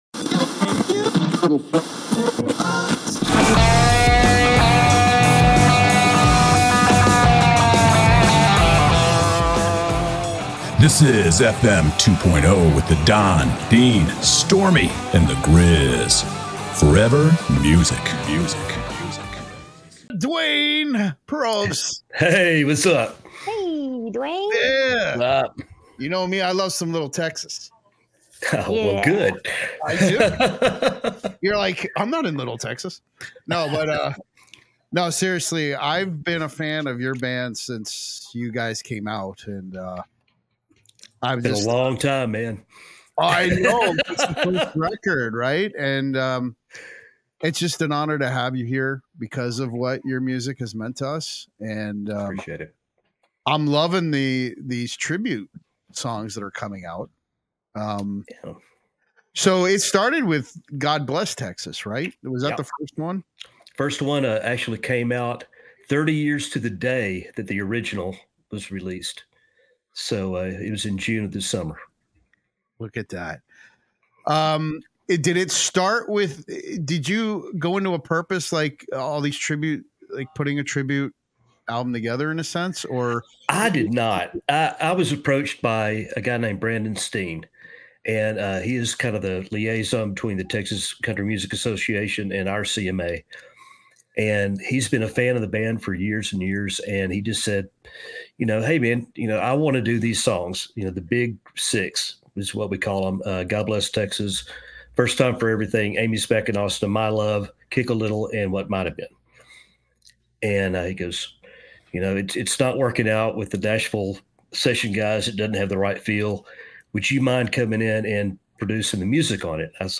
FM 2.0 Conversations